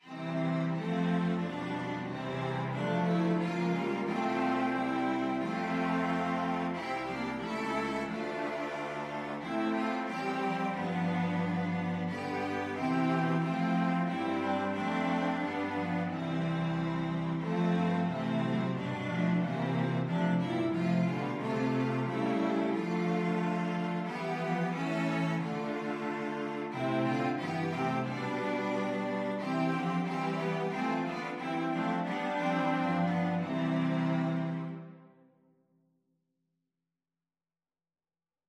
Free Sheet music for Cello Quartet